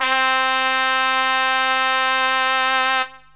SYNTH GENERAL-4 0011.wav